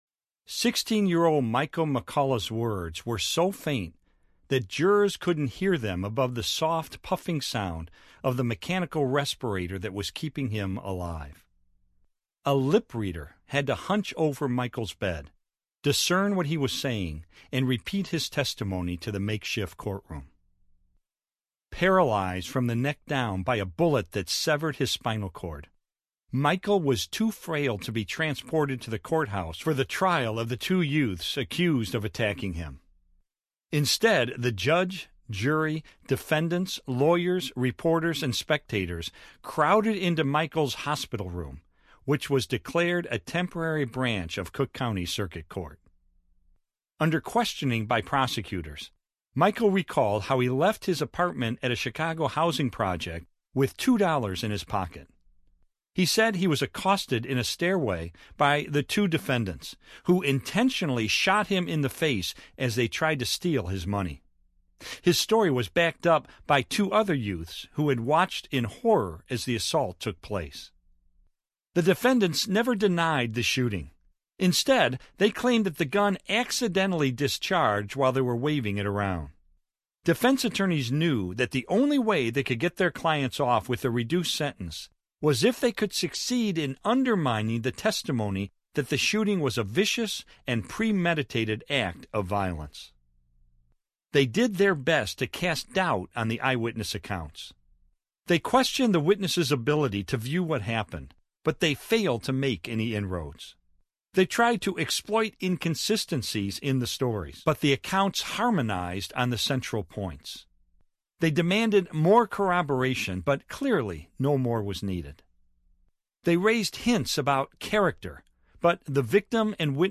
The Case for Christ Audiobook